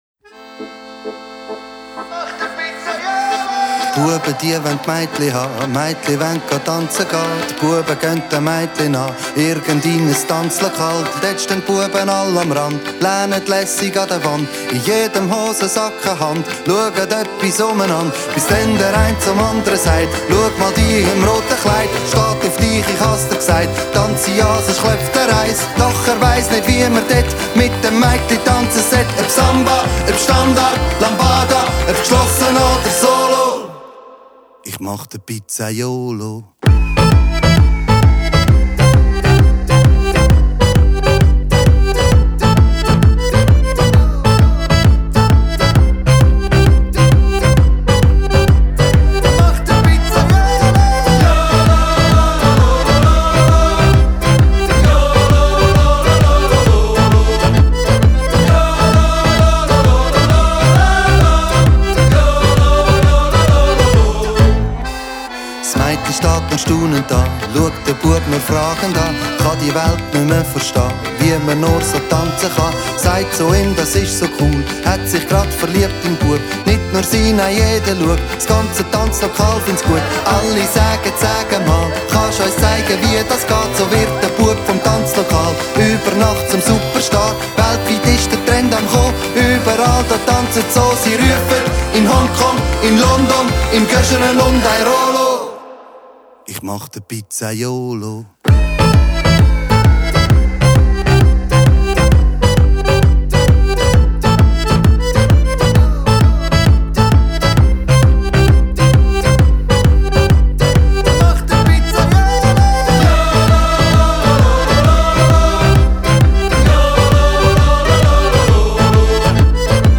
Partyhit